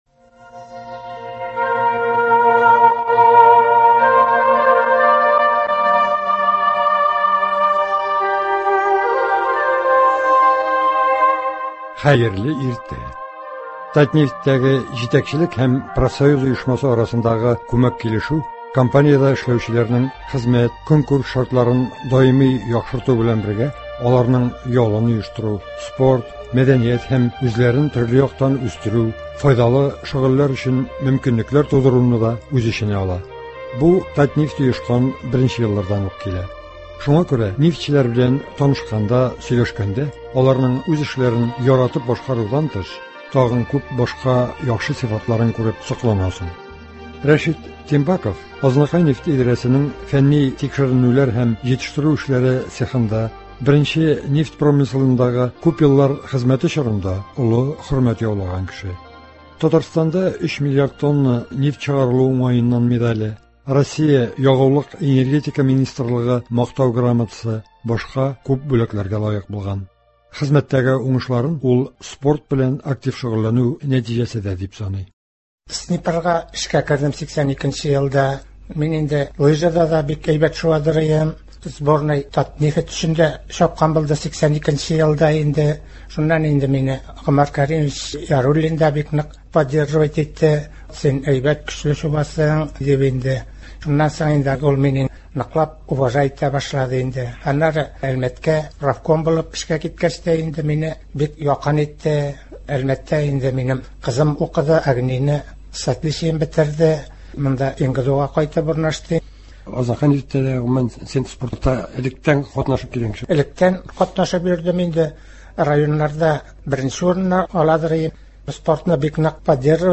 әңгәмә.